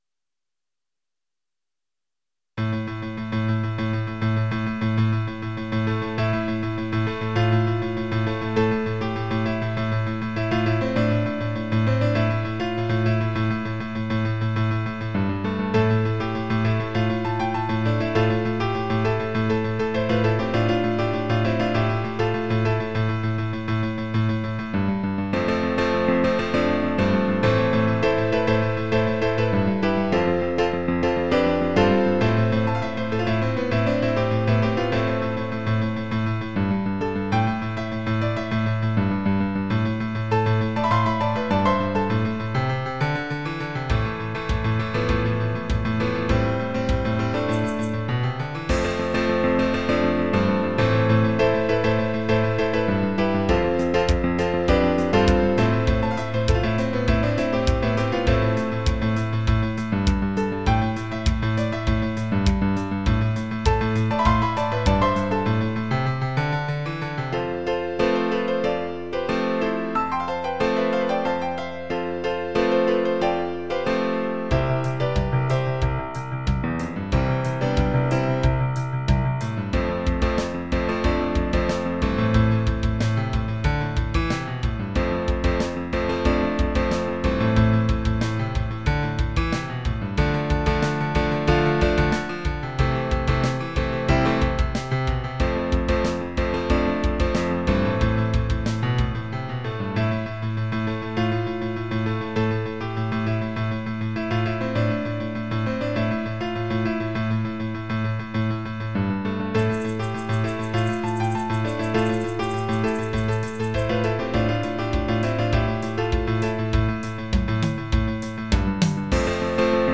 Title Apex Opus # 273 Year 0000 Duration 00:02:25 Self-Rating 5 Description Yeah, piano and drum kit.
Try the mp3. mp3 download wav download Files: mp3 wav Tags: Duet, Piano, Percussion Plays: 1402 Likes: 0